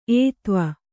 ♪ ētva